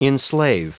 Prononciation du mot enslave en anglais (fichier audio)
Prononciation du mot : enslave